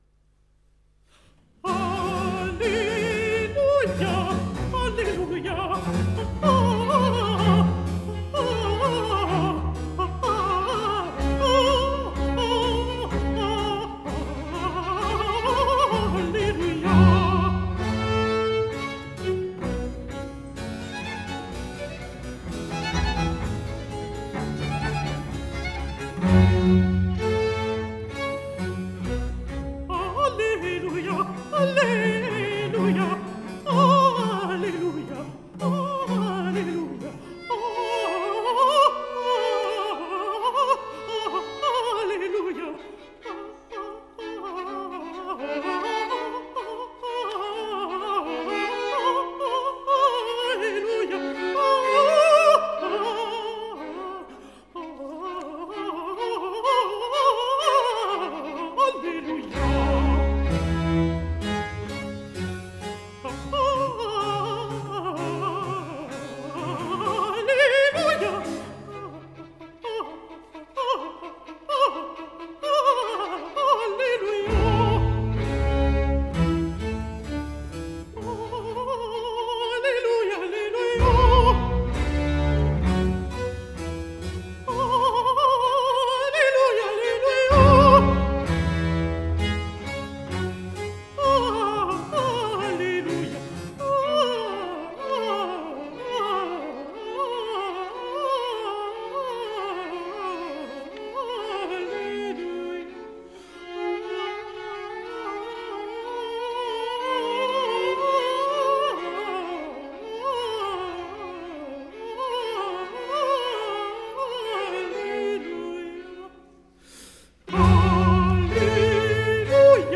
controtenore in registro di Contralto